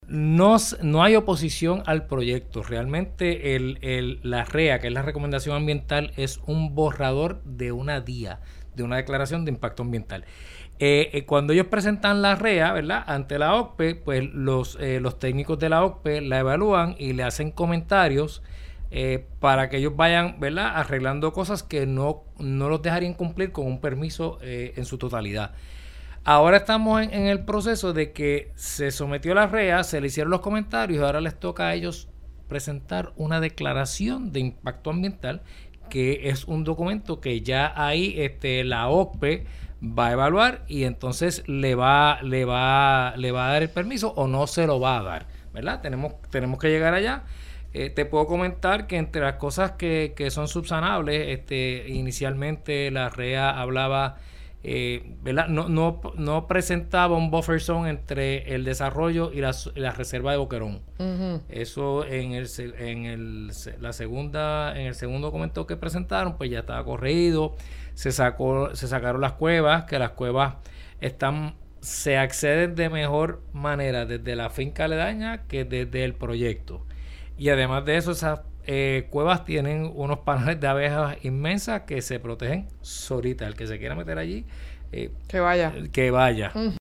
Ahora estamos en el proceso de que se sometió la REA, se le hicieron los comentarios, ahora les toca a ellos presentar una Declaración de Impacto Ambiental, que es un documento que ya ahí la OGPe va a evaluar y, entonces, le va a dar el permiso o no se lo va a dar“, indicó el secretario en El Calentón.